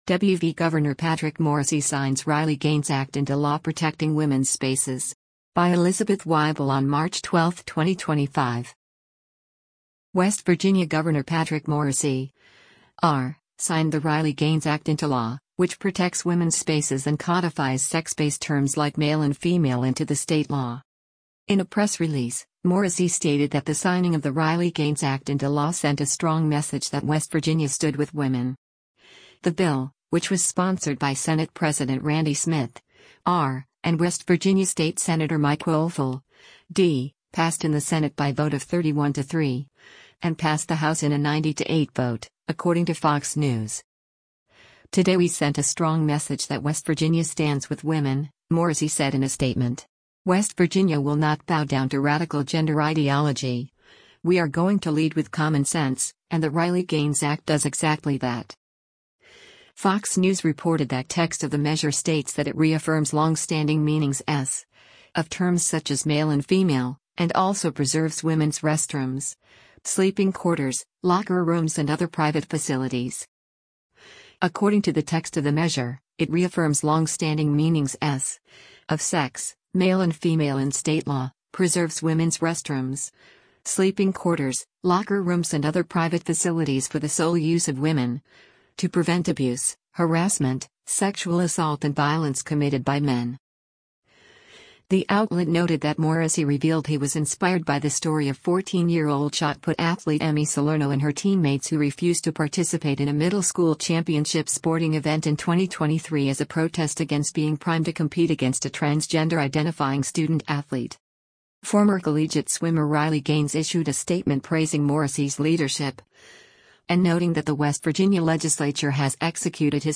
West Virginia Attorney General Patrick Morrisey at 2024 RNC